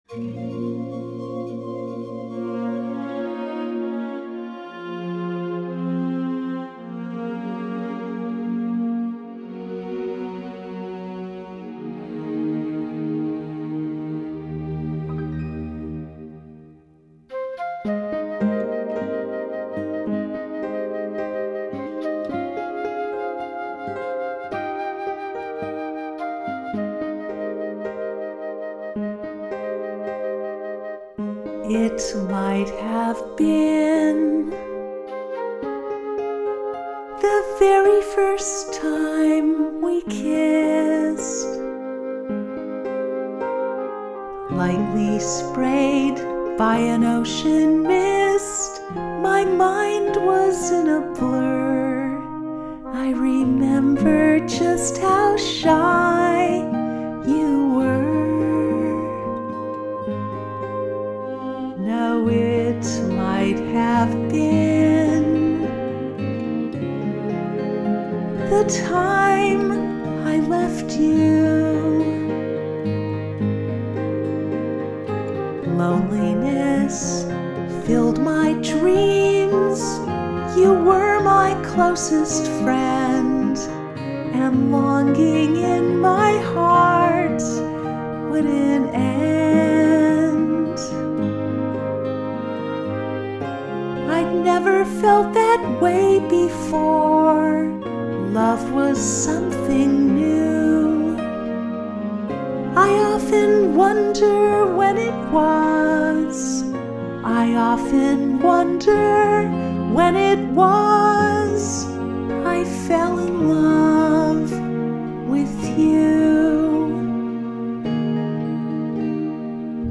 It Might Have Been Arrangement 2015